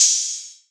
Open Hat 1 [ durag ].wav